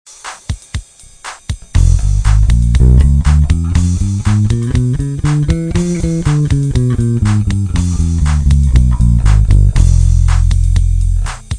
Les gammes à la basse: quel travail indispensable!!!
- Gamme diminuée
diminuee.wav